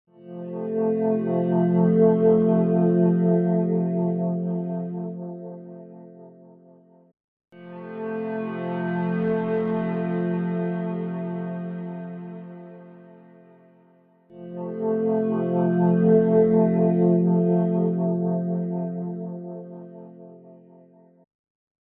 渦巻くクラシック・ロータリーサウンド
Rotary Mod | Bass Synth | Preset: Still Rotary
Rotary-Eventide-Pad-Bass-Still-Rotary.mp3